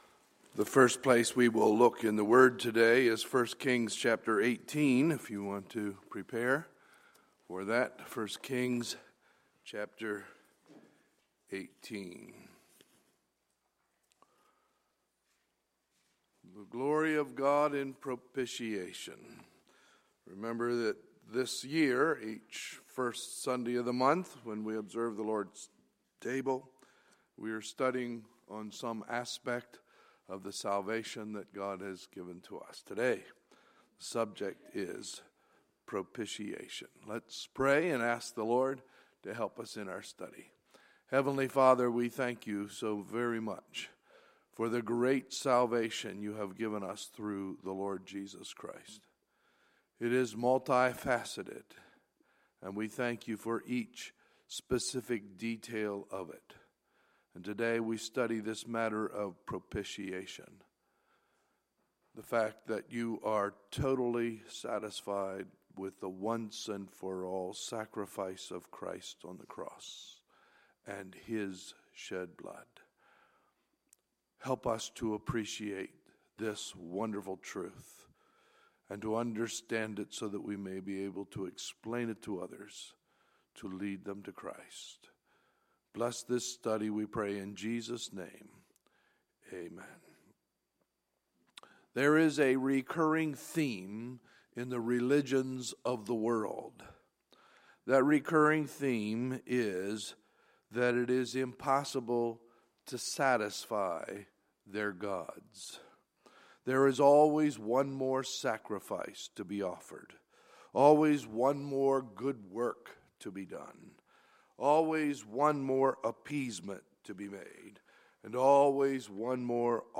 Sunday, May 1, 2016 – Sunday Morning Service
Sermons